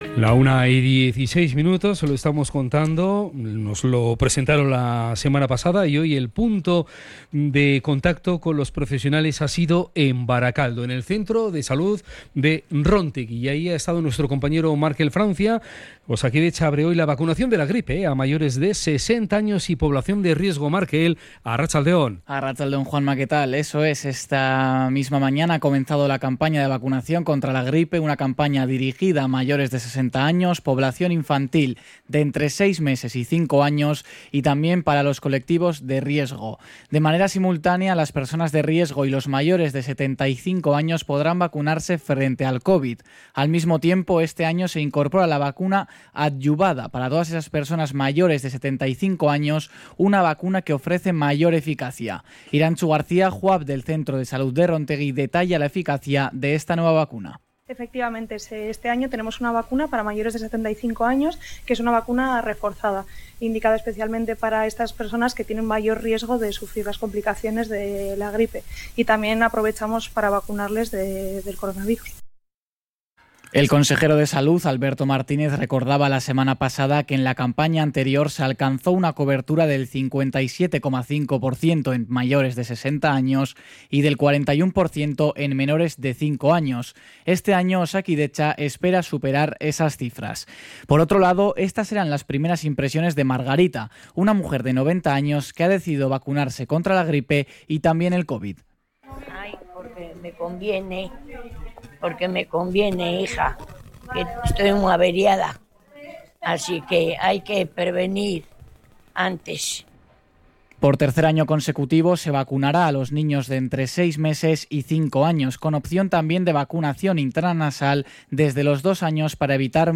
Herri Irratia – Radio Popular se ha desplazado al Centro de Salud de Rontegi donde se han administrado las primeras dosis de la vacuna contra el gripe y el covid.